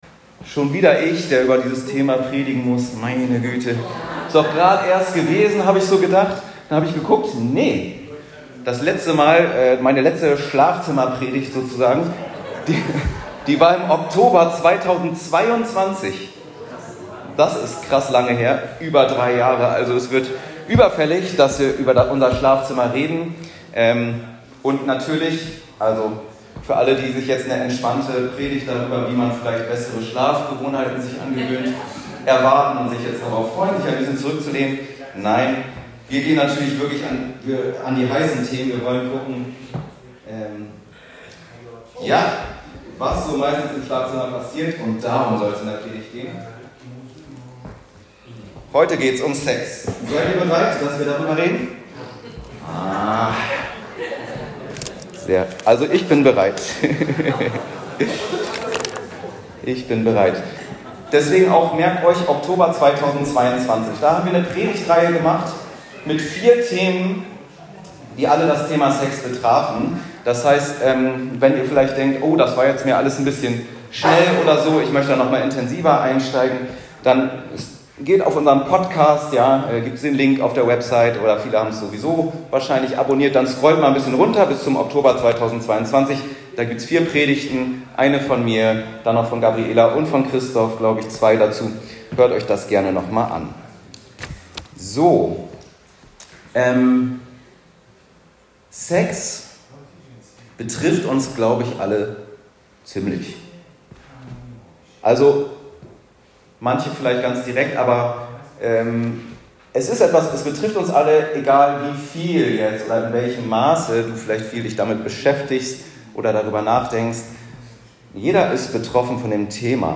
In dieser Predigt geht es um Sex.